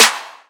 DDW Snare 1.wav